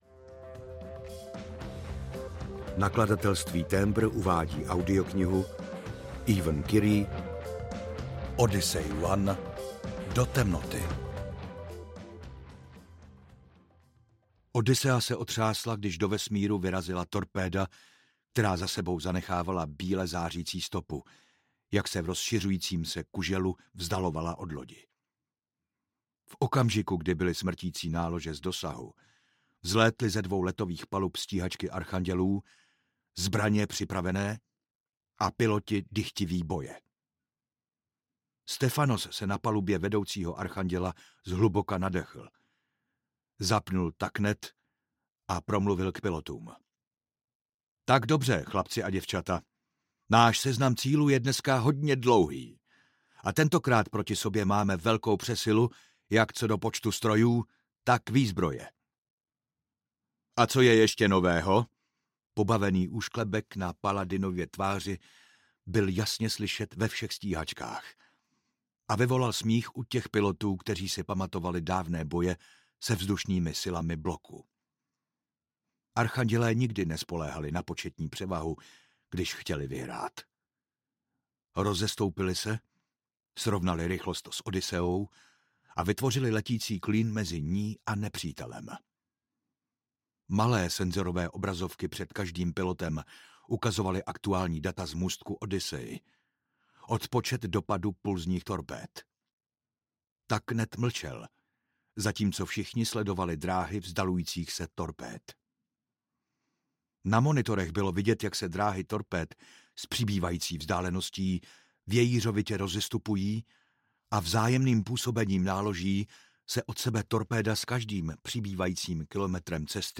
Odyssey One: Do temnoty audiokniha
Ukázka z knihy